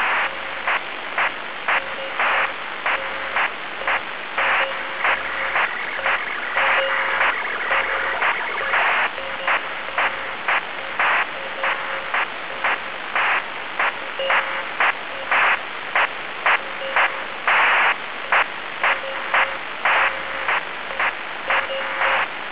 сигнал 4